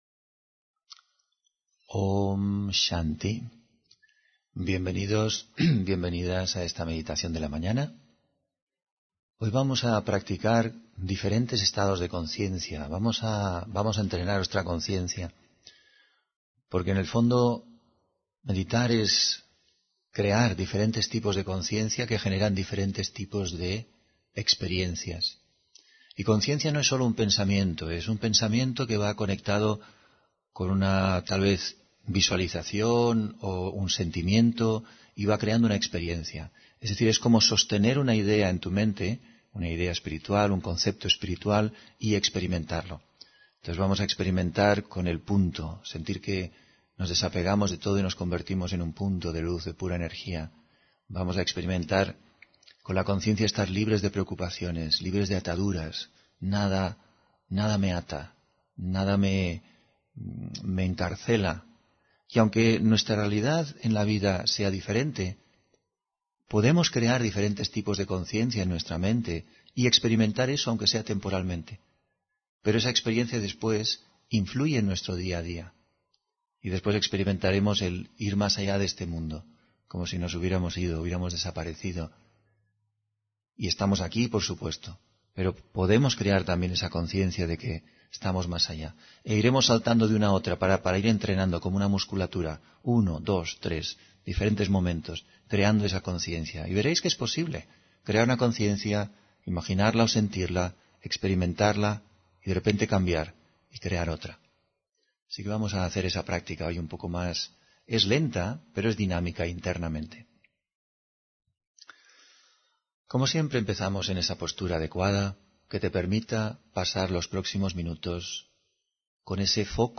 Meditación de la mañana: Haz sagrado cada momento y espacio